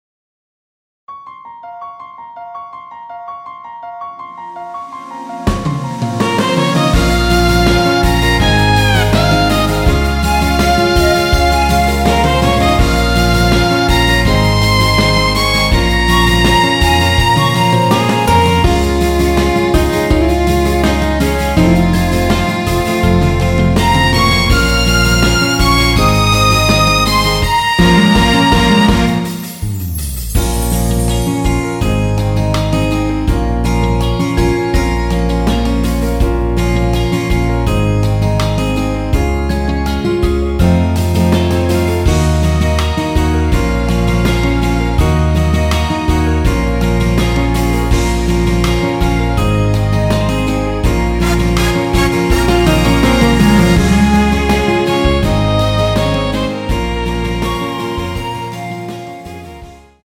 Bbm
앨범 | O.S.T
앞부분30초, 뒷부분30초씩 편집해서 올려 드리고 있습니다.
중간에 음이 끈어지고 다시 나오는 이유는